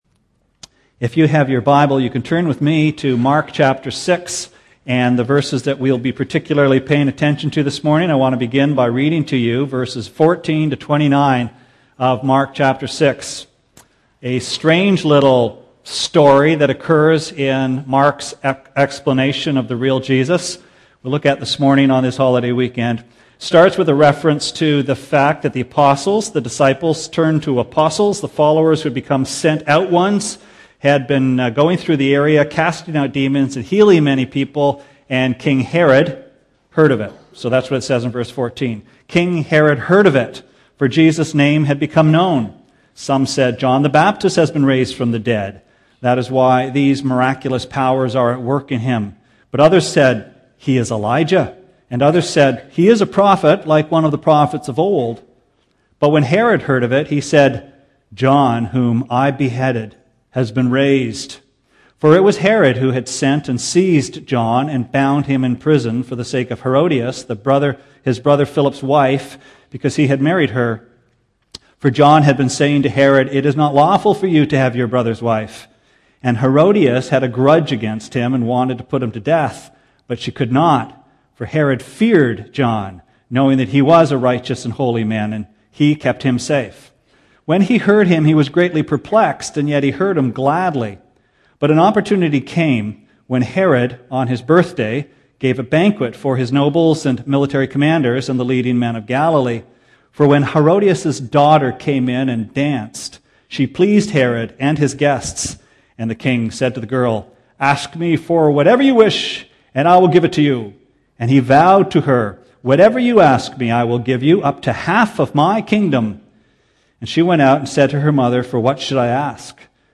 Sermon Archives May 17